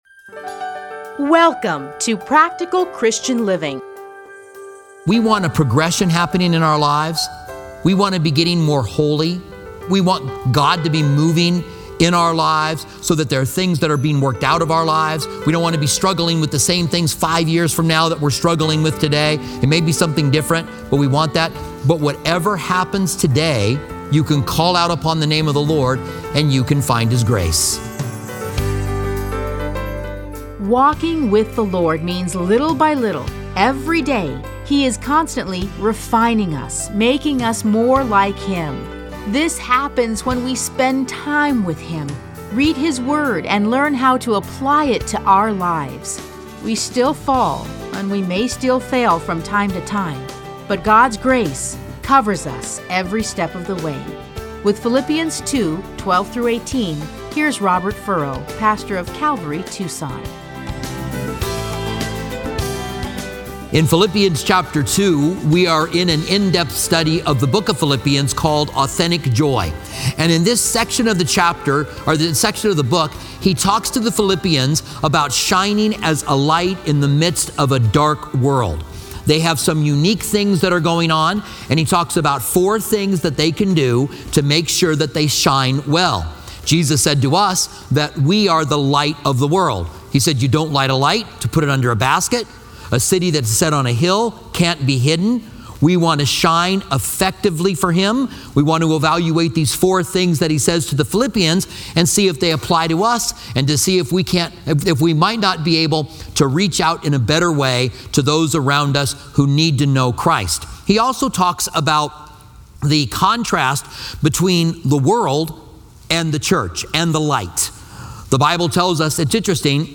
Listen to a teaching from A Study in Philippians 2:12-18.